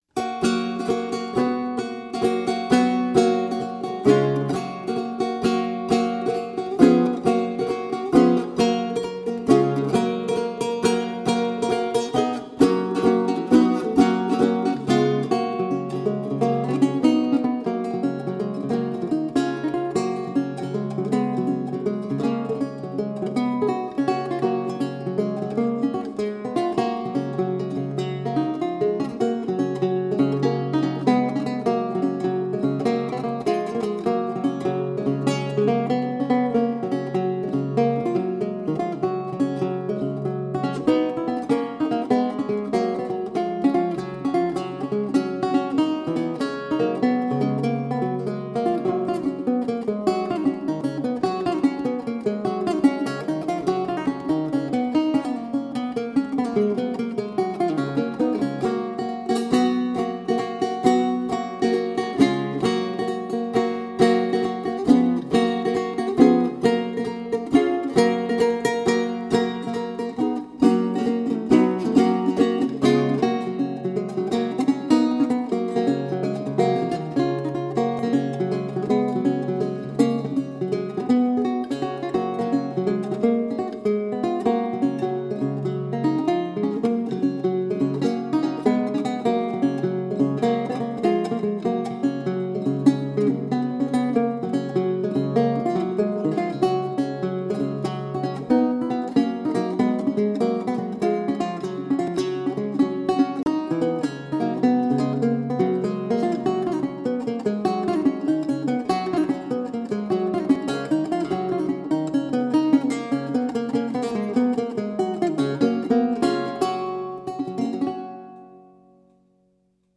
Guitarist
Canarios – Another home recording, and all ‘one takes’ with no edits; from 2004.
The Baroque guitar is a five string instrument essentially, but each string is doubled.  What would be the low A on a classical guitar is tuned an octave higher.  There is no low E.  The Baroque guitar is essentially a treble instrument, with no bass register to speak of.